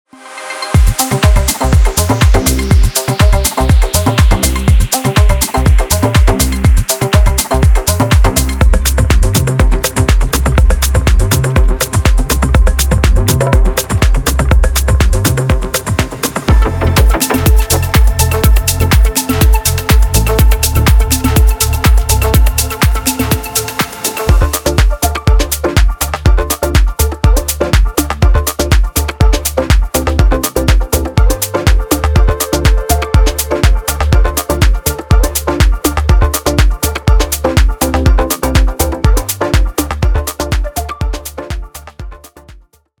A stunning evolution of organic rhythm and texture — packed with groove, vibe, and endless usability.
From acoustic percussion and bongos to glitch FX, groovy tops, and wonderful Serum 2 presets, every sound stands apart — full of character, balanced, and ready to drop straight into your mix.
•380 Acoustic Percussions (Hats, Toms, Cajon, Clave…)